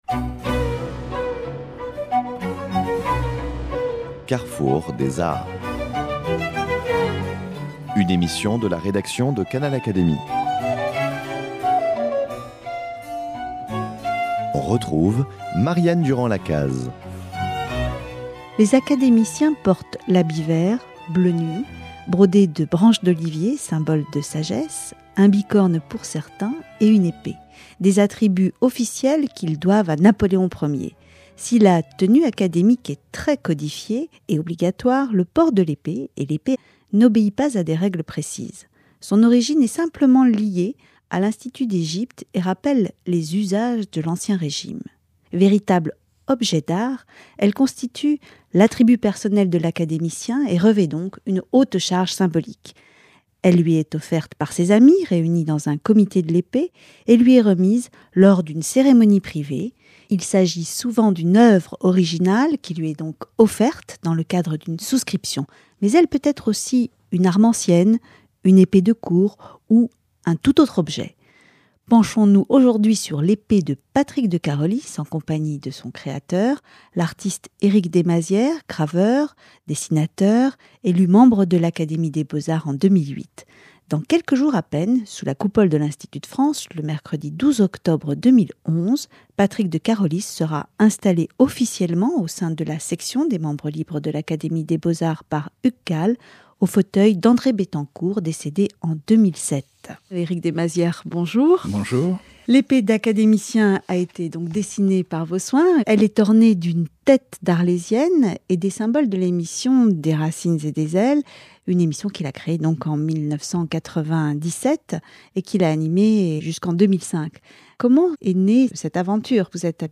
L’artiste lève le voile dans cette émission sur cet exercice périlleux, excitant et au final bien satisfaisant.